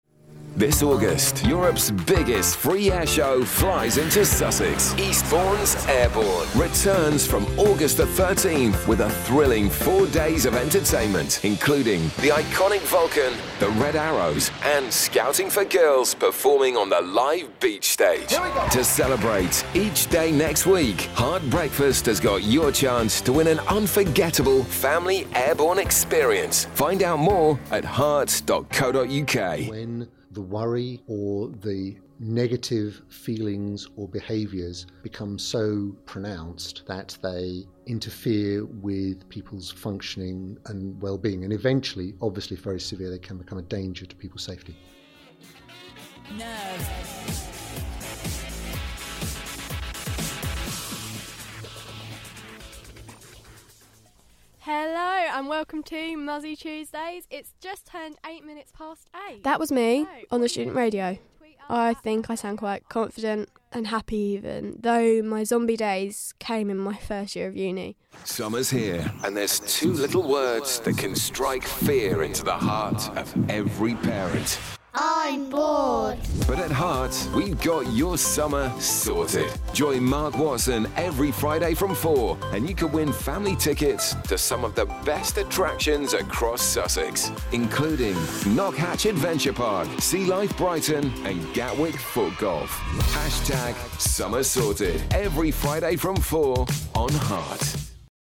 Please find below a short show reel.
Show-reel-Mixdown-1.mp3